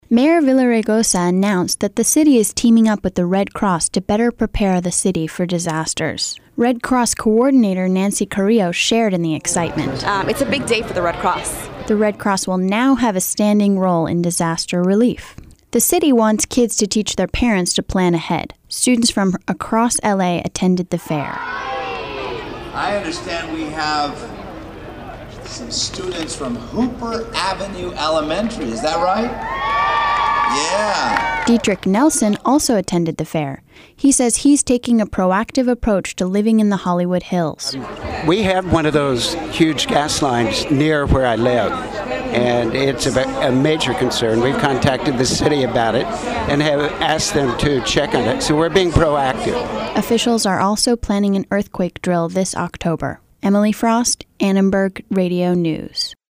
As part of the National Preparedness Month, the Department of Emergency Management hosted an emergency readiness fair at L.A. Live Thursday.